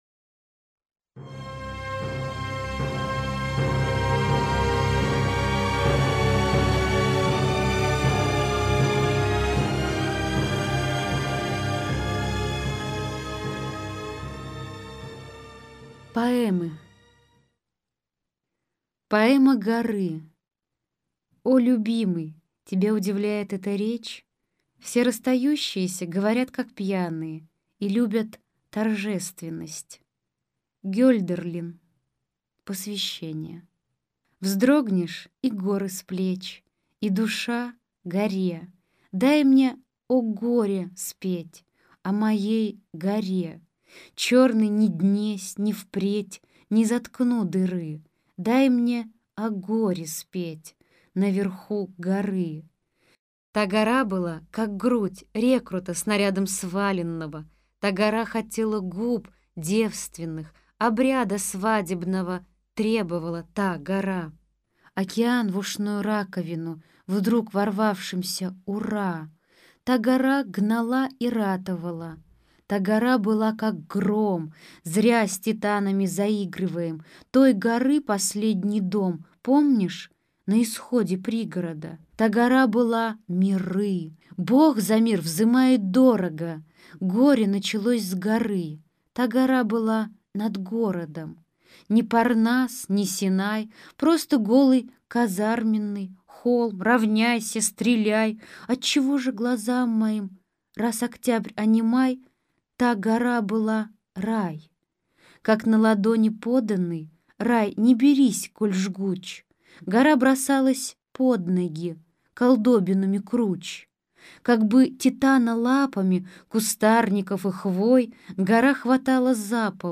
Аудиокнига Стихи | Библиотека аудиокниг